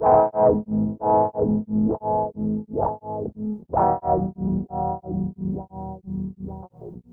65WHA -GTR-R.wav